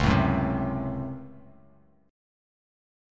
DbM7sus4#5 chord